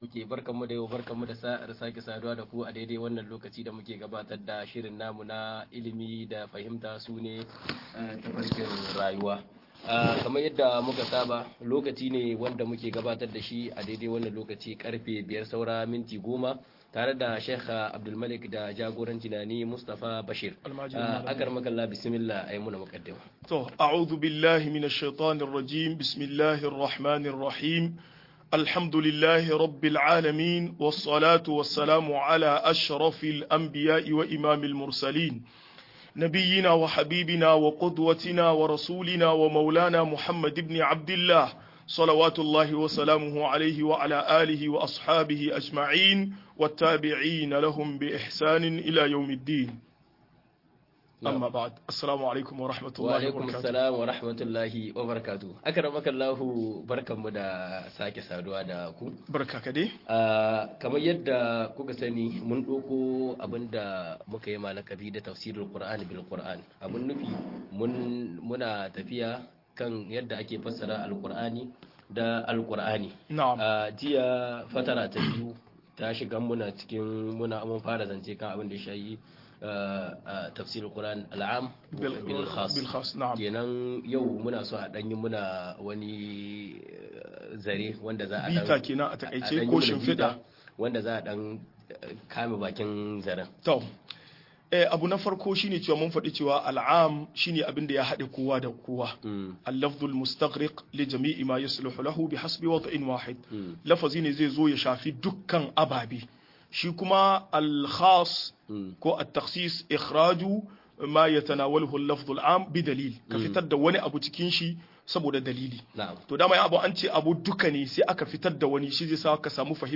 Ka'idodin fassara Alkur'ani-02 - MUHADARA